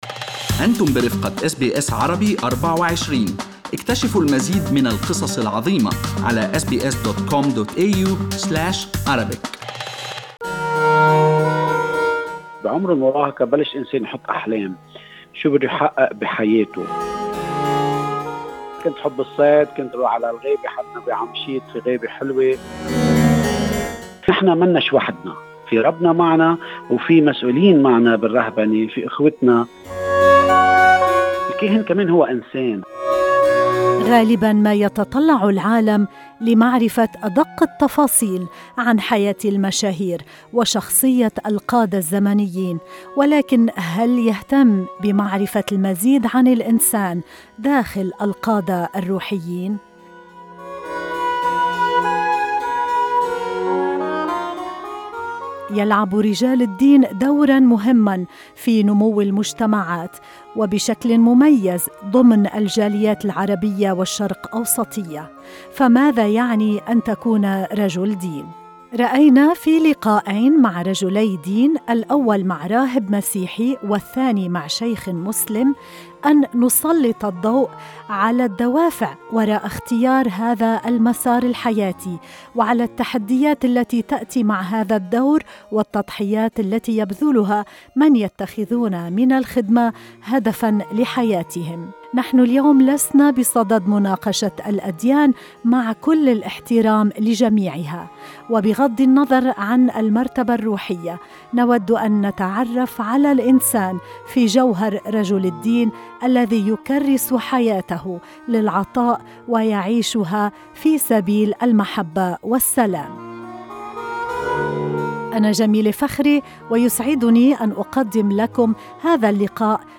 رأينا في لقائين مع رجلي دين، الأول مع راهب مسيحي والثاني مع شيخ مسلم، أن نسلط الضوء على الدوافع وراء اختيار هذا المسار الحياتي وعلى التحديات التي تأتي مع هذا الدور والتضحيات التي يبذلها من يتخذون من الخدمة هدفا لحياتهم.